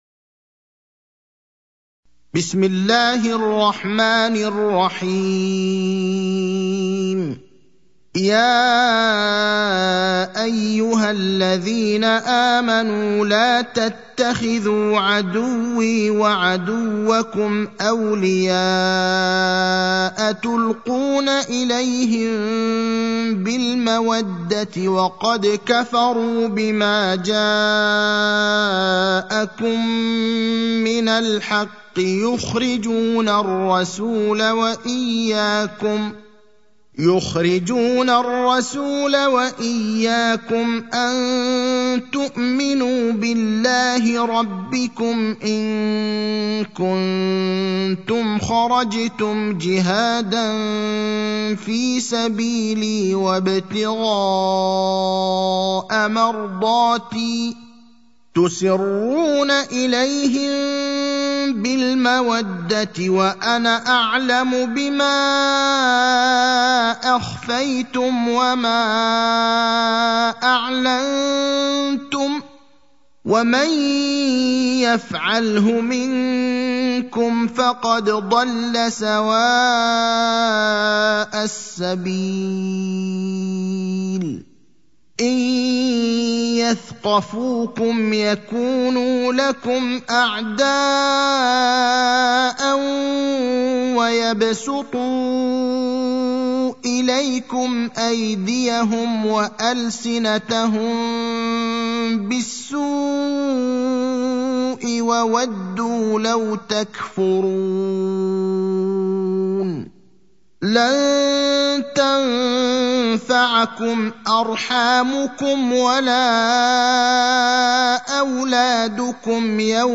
المكان: المسجد النبوي الشيخ: فضيلة الشيخ إبراهيم الأخضر فضيلة الشيخ إبراهيم الأخضر الممتحنة (60)